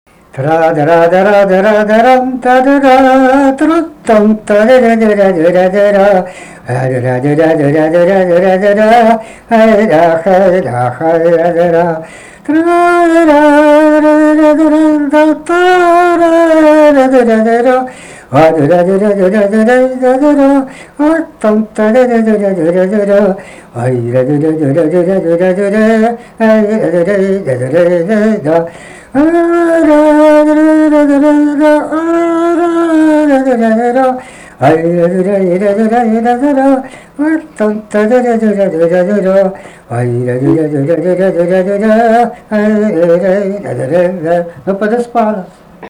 Padispano melodija
šokis